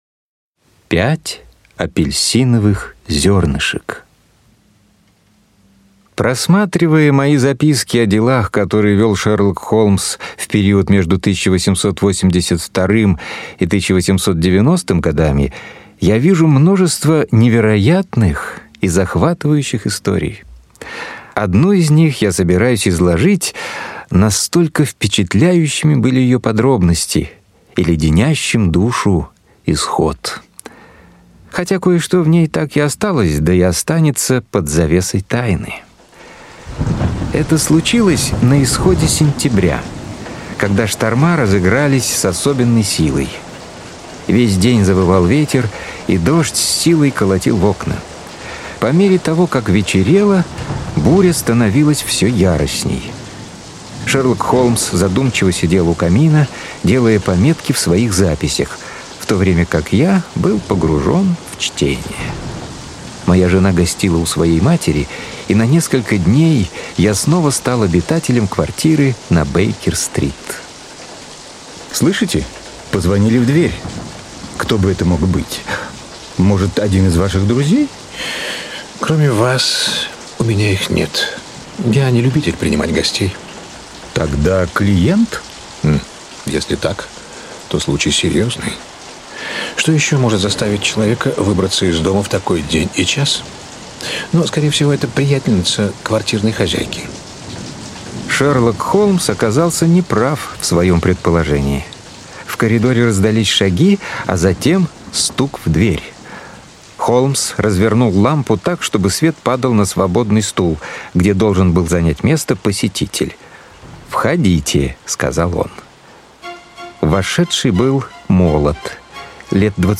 Аудиокнига Пять апельсиновых зернышек (спектакль) | Библиотека аудиокниг
Aудиокнига Пять апельсиновых зернышек (спектакль) Автор Артур Конан Дойл Читает аудиокнигу Алексей Веселкин.